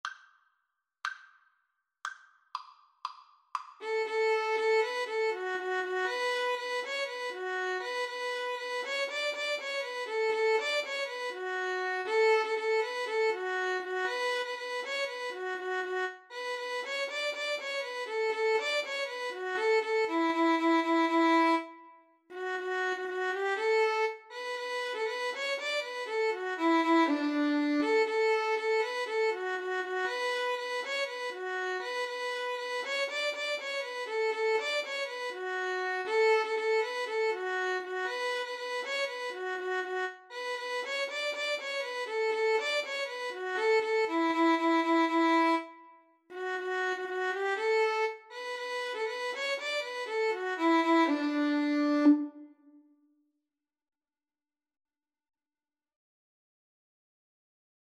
Free Sheet music for Violin-Cello Duet
D major (Sounding Pitch) (View more D major Music for Violin-Cello Duet )
2/2 (View more 2/2 Music)
Allegro =c.120 (View more music marked Allegro)
Traditional (View more Traditional Violin-Cello Duet Music)
Irish